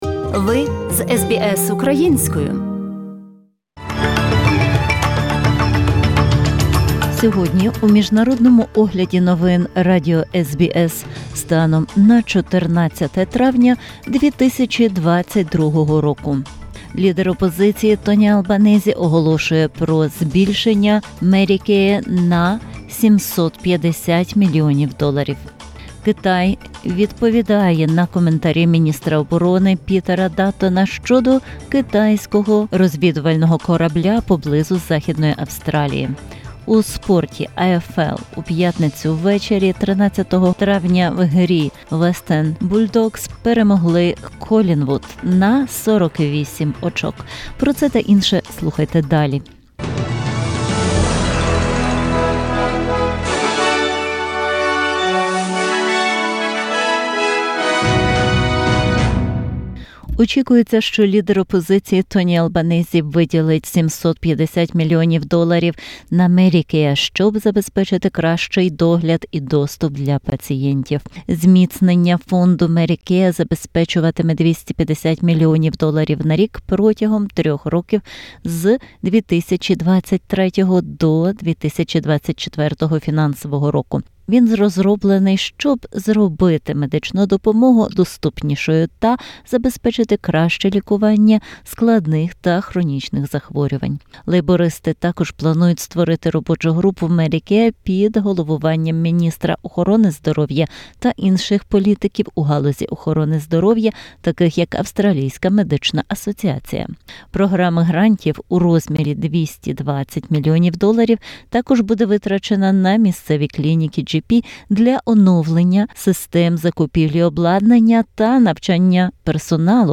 SBS новини українською - 14/05/2022